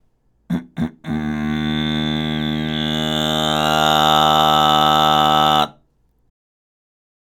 最後に、「ん」の状態で咳払いして、ノイズを作り、その音を「あ」で保ちます。
※「ん」⇒「あ」のノイズの見本音声
10_kaseitai_noise_nnA.mp3